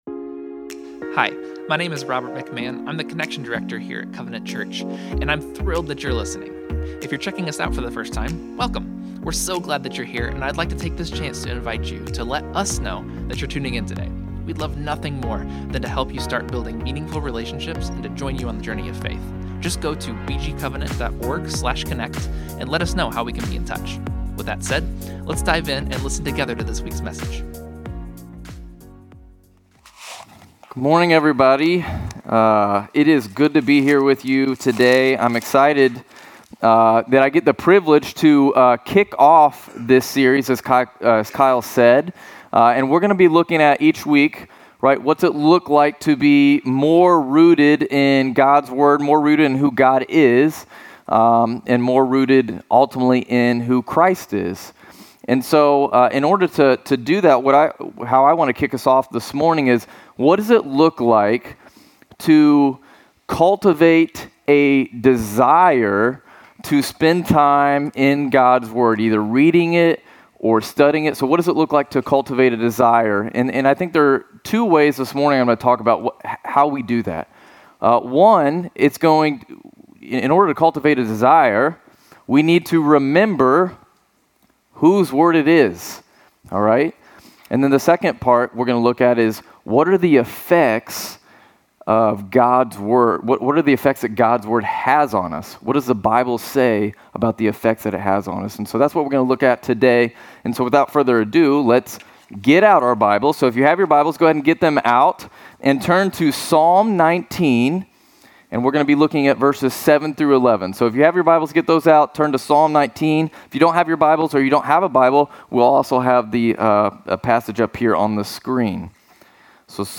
From Series: "Single Sermons"